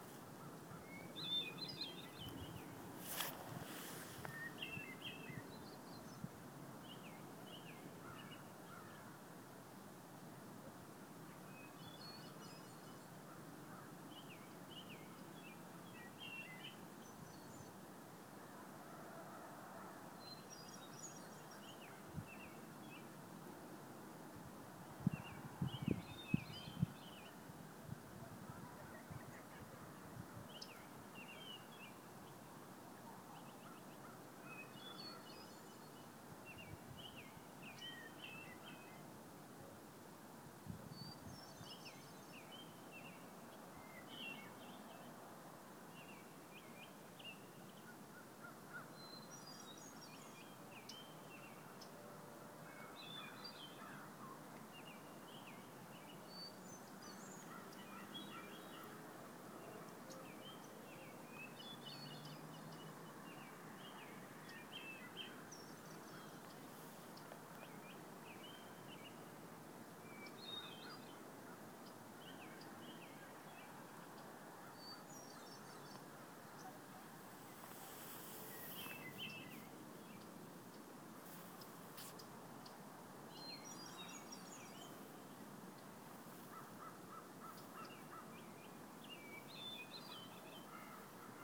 DuskChorus201205.m4a
Dusk Chorus, Halifax 2012-05-04